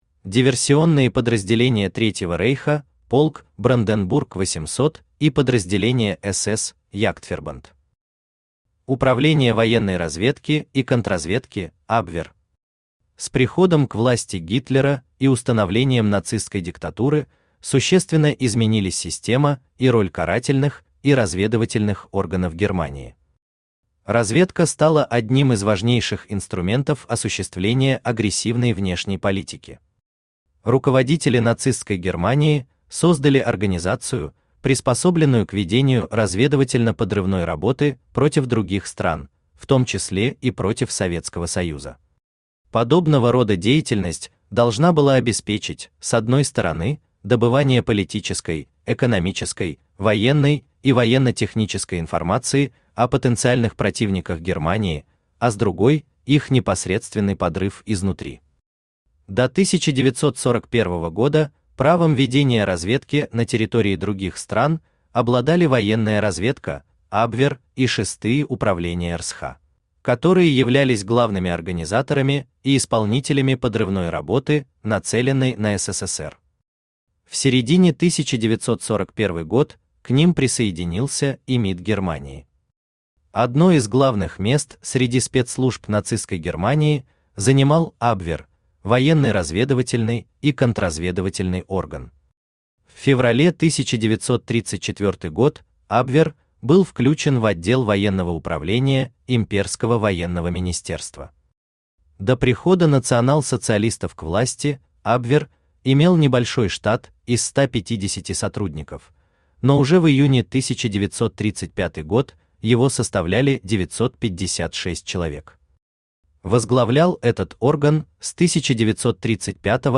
Аудиокнига Диверсия – мое ремесло: полк «Бранденбург 800 и другие» | Библиотека аудиокниг
Aудиокнига Диверсия – мое ремесло: полк «Бранденбург 800 и другие» Автор Денис Соловьев Читает аудиокнигу Авточтец ЛитРес.